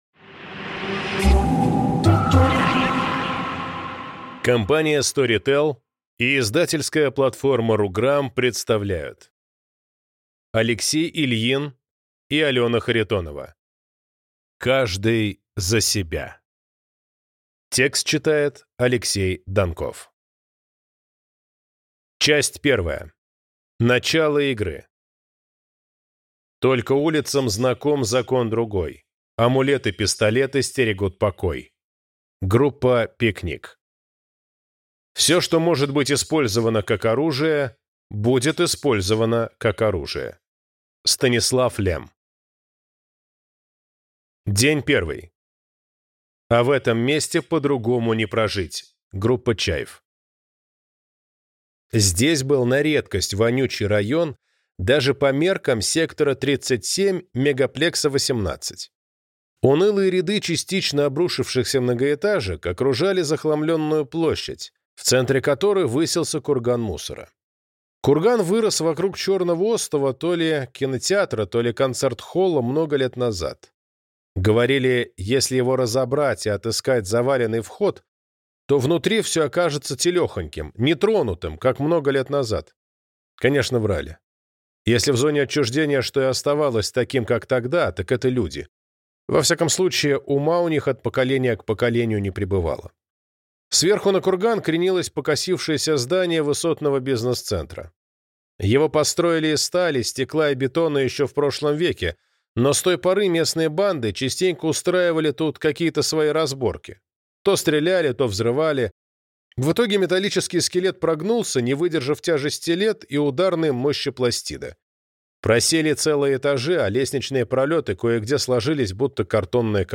Аудиокнига Каждый за себя. Начало игры | Библиотека аудиокниг